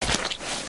sounds / material / human / step / dirt02gr.ogg
dirt02gr.ogg